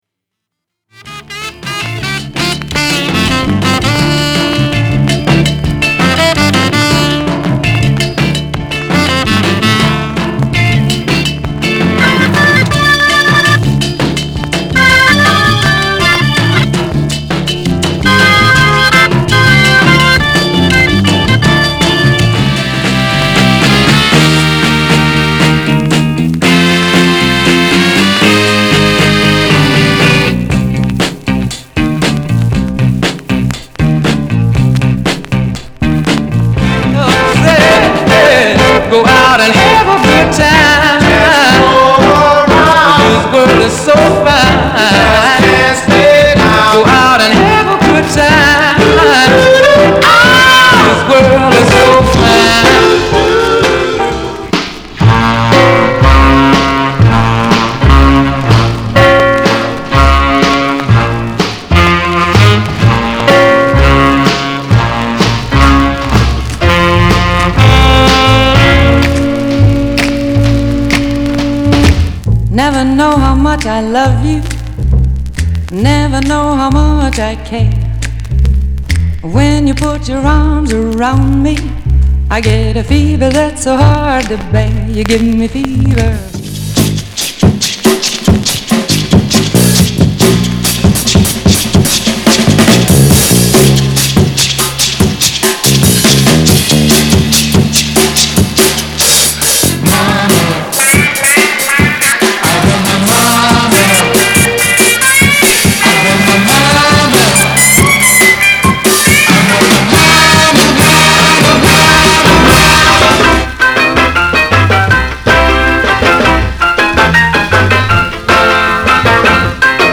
category Vocal
Yahoo Bid Music Vinyl Records Jazz Vocal